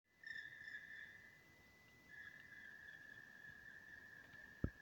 Melnā dzilna, Dryocopus martius
Ziņotāja saglabāts vietas nosaukumsMežs pie P101